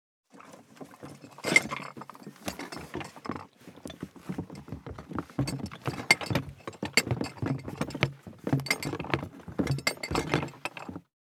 179,ゴロゴロ,ガタガタ,ドスン,バタン,ズシン,カラカラ,ギィ,ゴトン,キー,ザザッ,ドタドタ,バリバリ,カチャン,ギシギシ,ゴン,ドカン,ズルズル,タン,パタン,ドシン,
効果音荷物運び